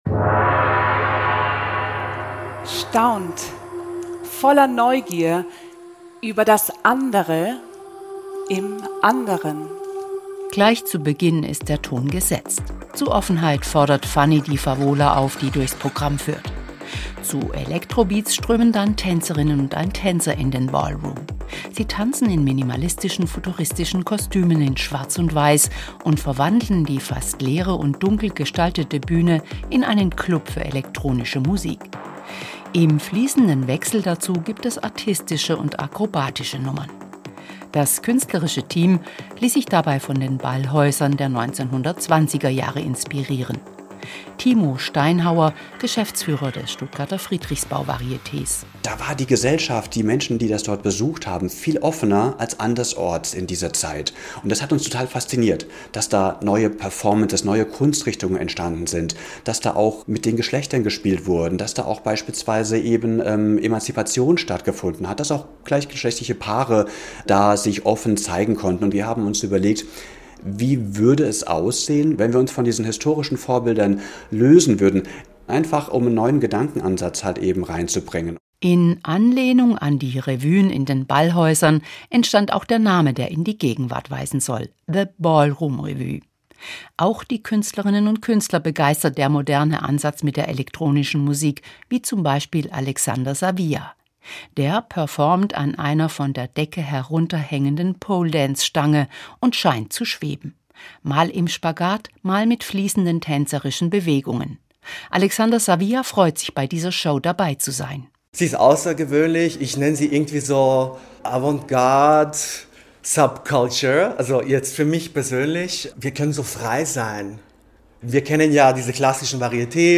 Modernes Varieté mit den treibenden Beats der elektronischen Musik im Stuttgarter Friedrichsbau Varieté – dazu mitreißender Tanz, anspruchsvolle Artistik und Akrobatik.
Die treibenden Beats der Musik ziehen sich durch die ganze Revue.